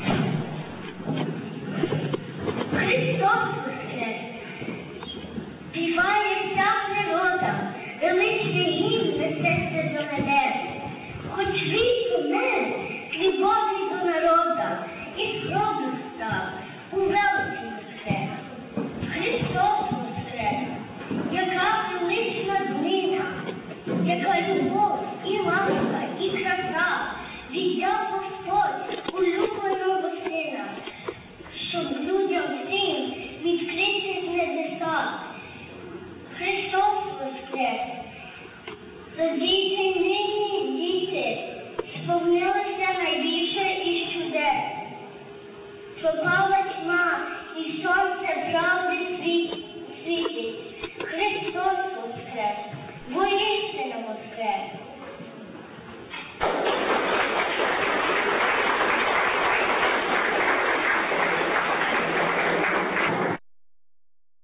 Після вечері та свяченого відбулися виступи дітей та молоді, а також загальна молитва.